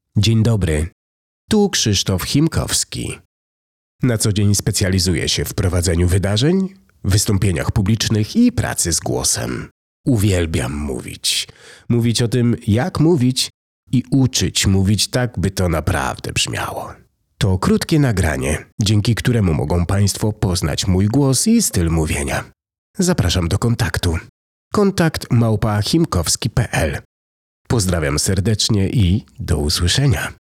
mojej audio wizytówki